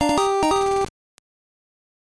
Звук прохождения уровня